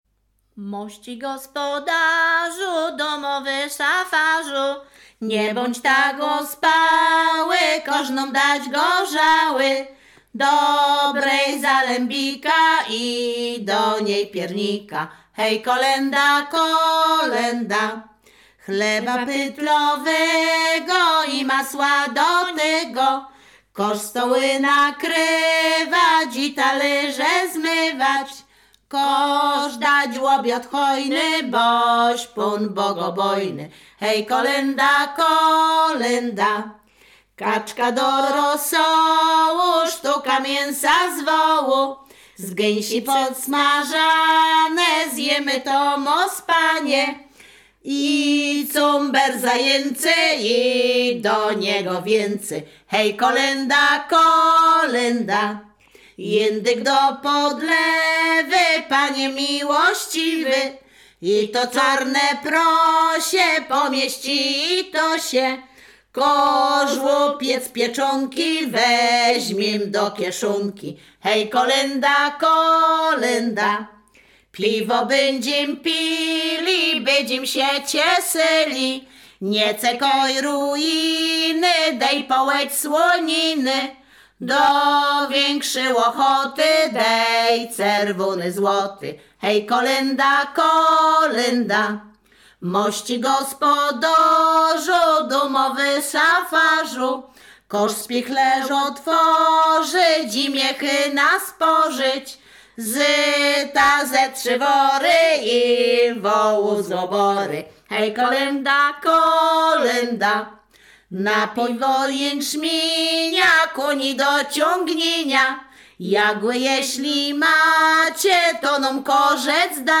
Śpiewaczki z Chojnego
województwo łódzkie, powiat sieradzki, gmina Sieradz, wieś Chojne
Kolęda życząca